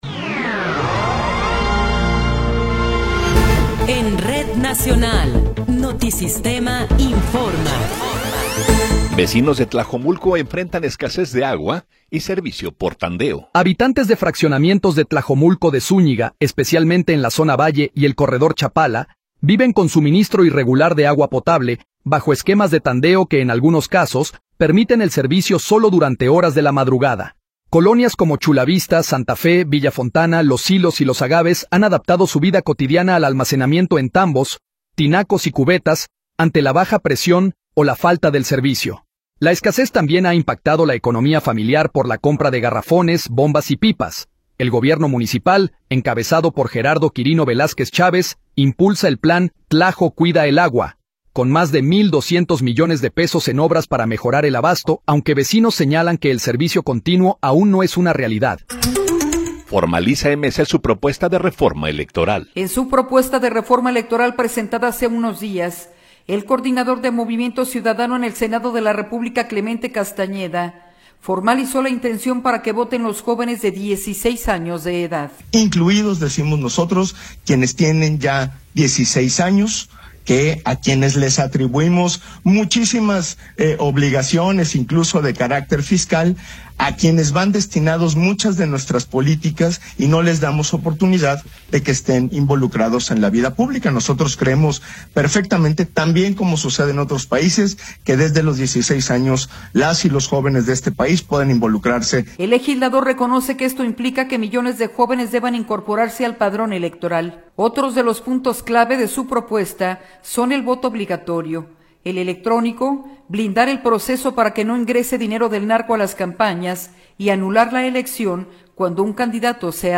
Noticiero 11 hrs. – 9 de Marzo de 2026
Resumen informativo Notisistema, la mejor y más completa información cada hora en la hora.